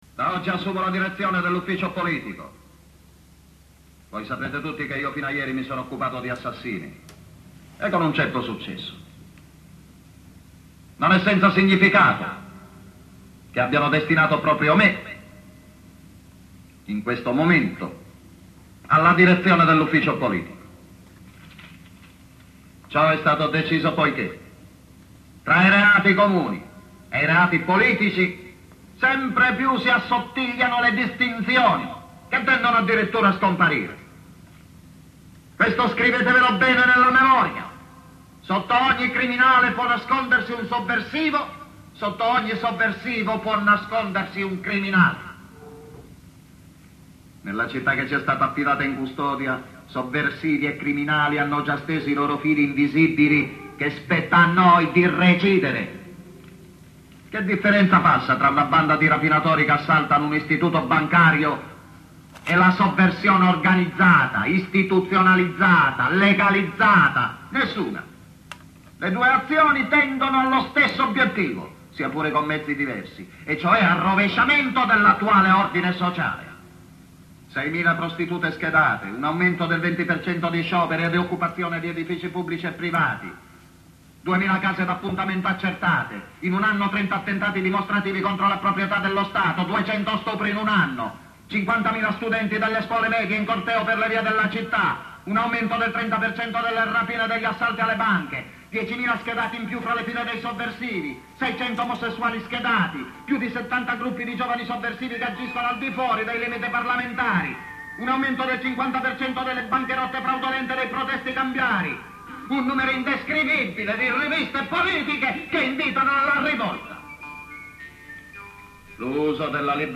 un piccolo collage...
Il discorso è del grande Gian Maria Volontè.